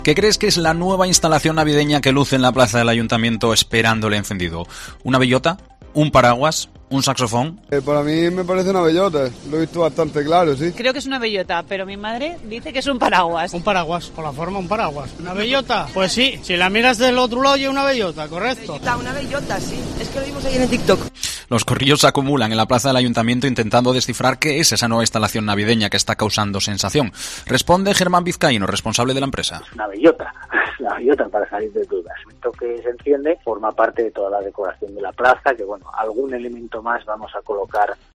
Opinan los ovetenses: ¿Qué figura es la que luce en la Plaza del Ayuntamiento?